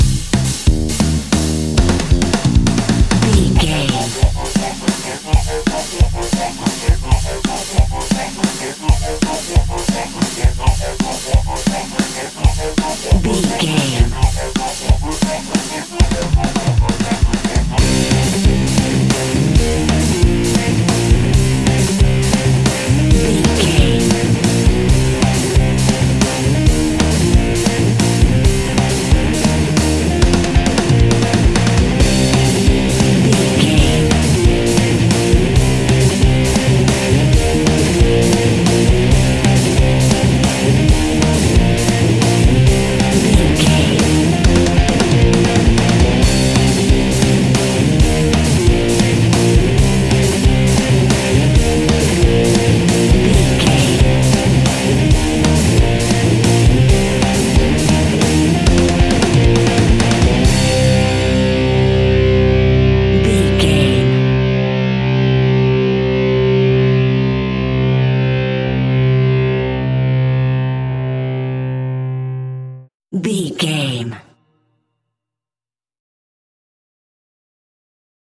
Epic / Action
Aeolian/Minor
hard rock
heavy metal
blues rock
distortion
rock instrumentals
Rock Bass
heavy drums
distorted guitars
hammond organ